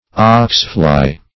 oxfly - definition of oxfly - synonyms, pronunciation, spelling from Free Dictionary Search Result for " oxfly" : The Collaborative International Dictionary of English v.0.48: Oxfly \Ox"fly`\, n. (Zool.) The gadfly of cattle.